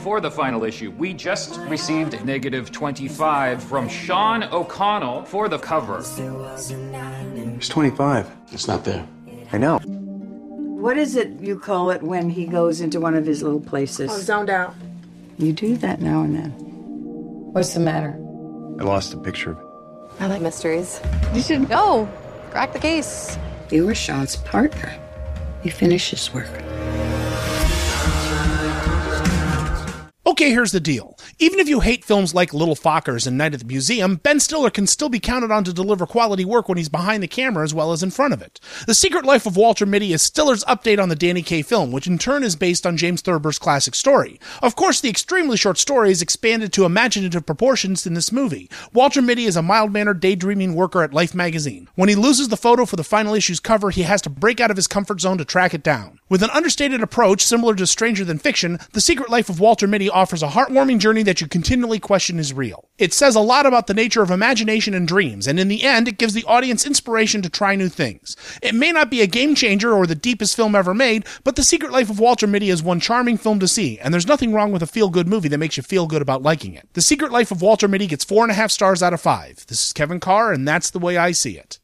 ‘The Secret Life of Walter Mitty’ Movie Review